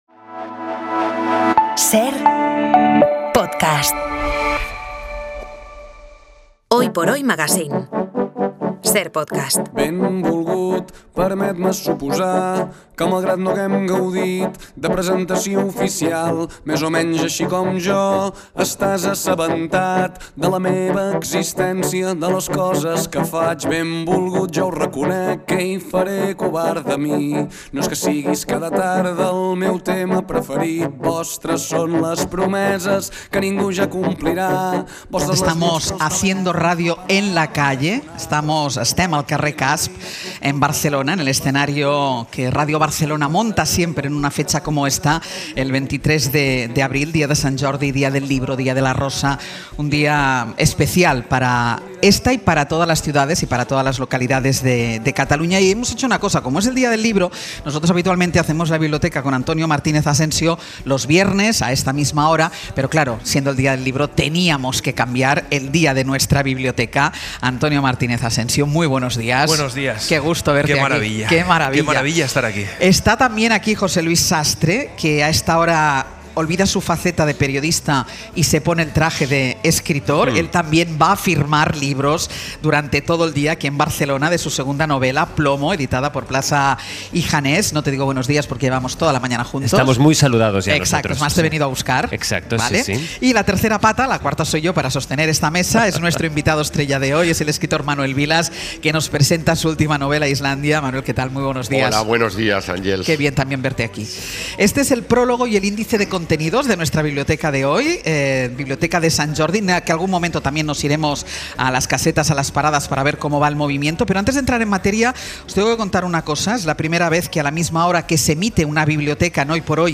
Y nos ha presentado la novela en el escenario de Radio Barcelona en el día del libro, el 23 de abril, Sant Jordi.